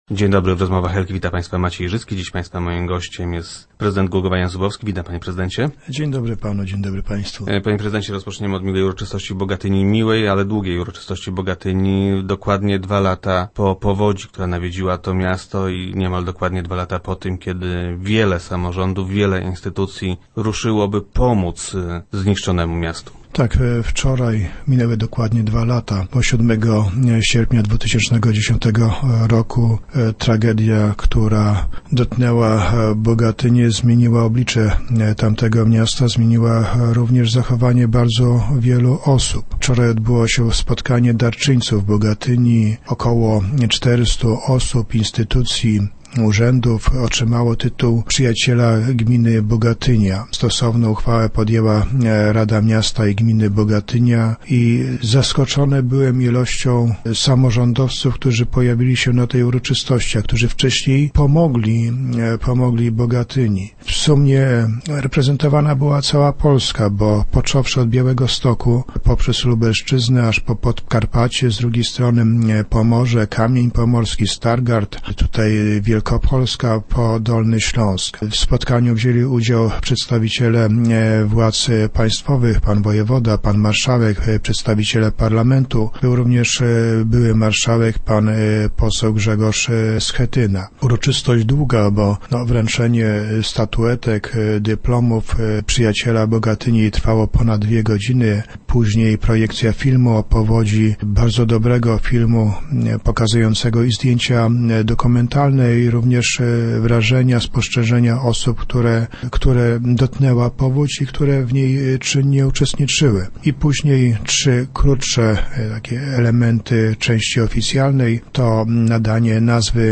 - Wśród samorządów, które pomogły, był także Głogów - mówi prezydent Jan Zubowski.